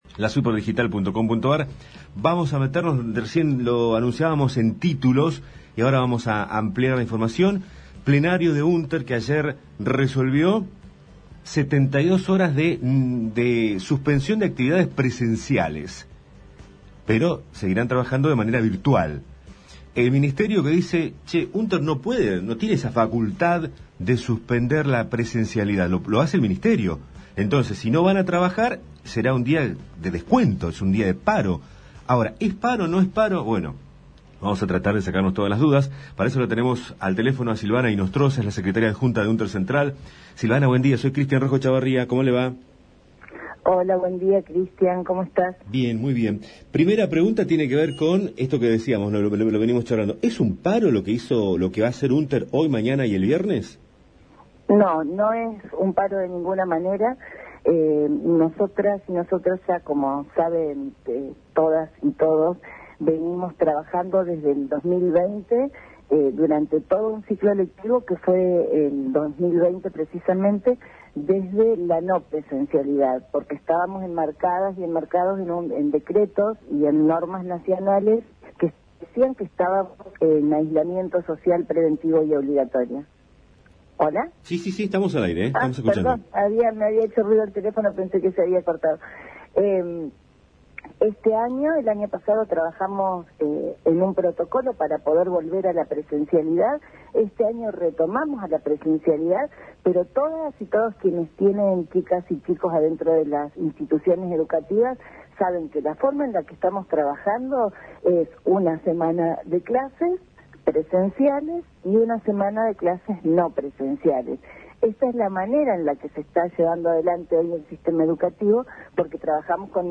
entrevistas radiofónicas sobre definición plenario